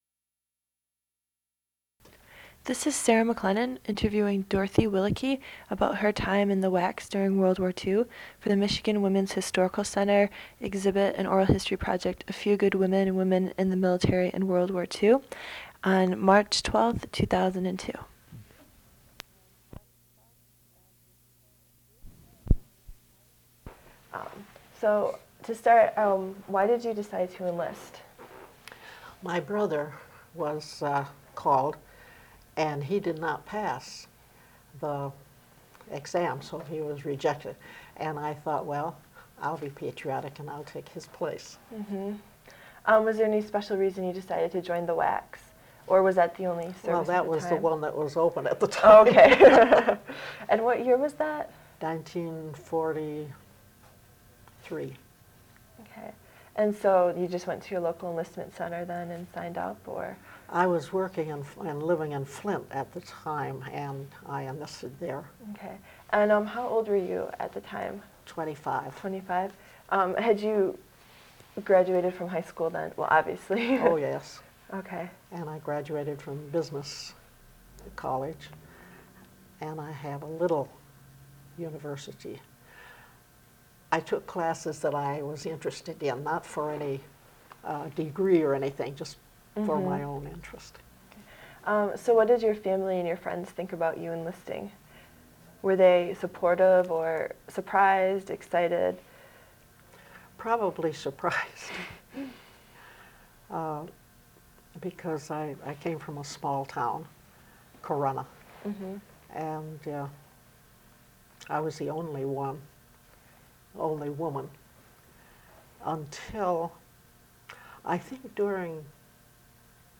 Oral history interview
Oral histories (literary genre) Interviews